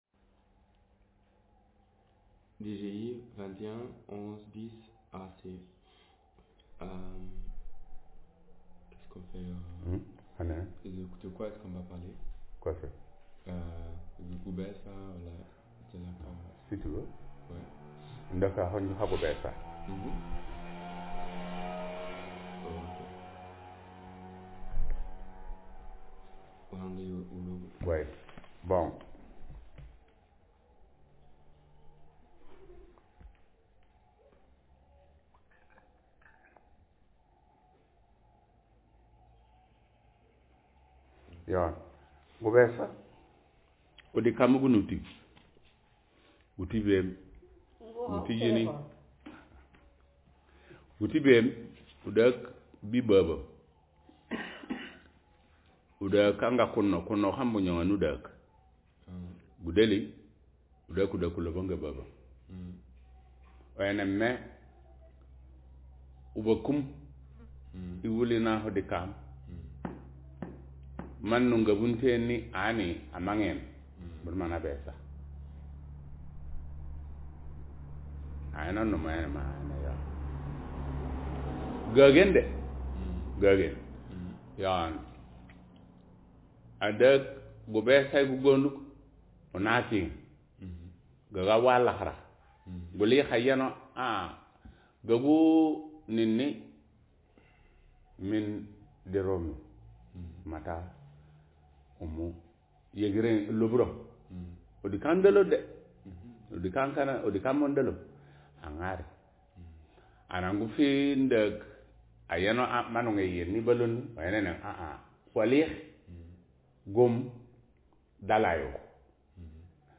Speaker sex m Text genre procedural